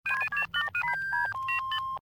hail.ogg